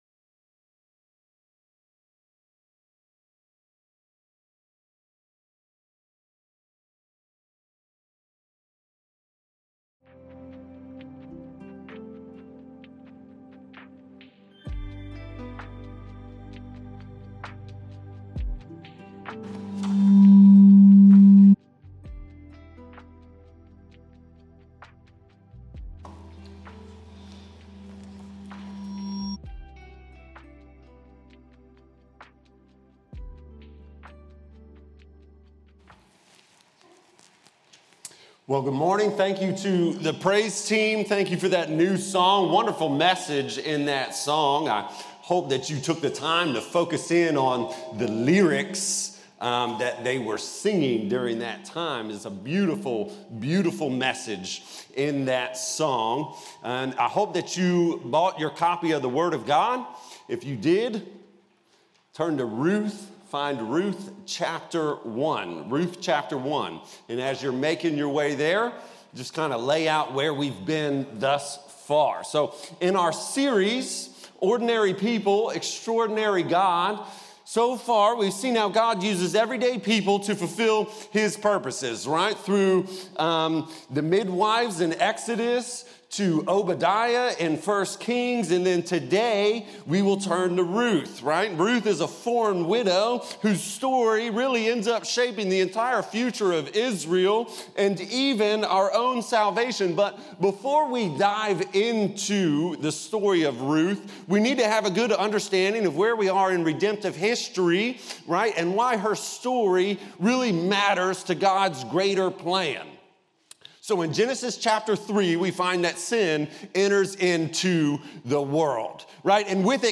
Sermons | Battlefield Baptist Church